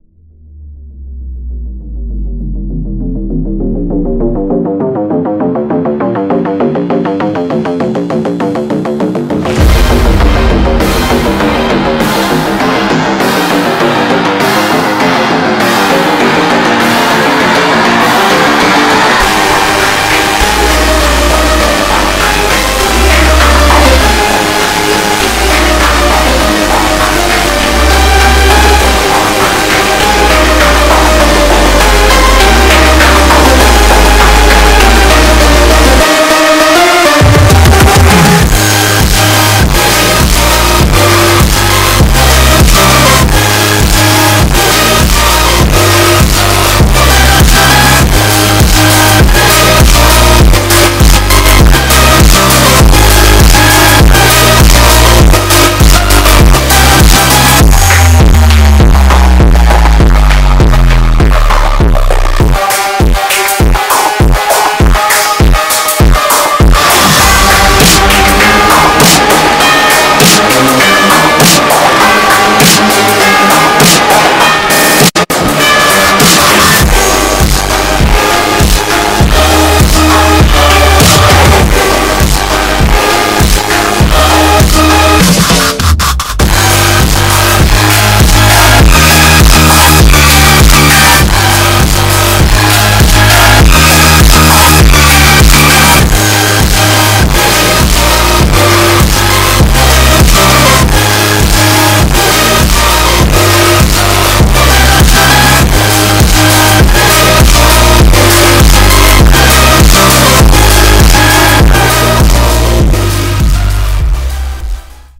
frontier-earrape.mp3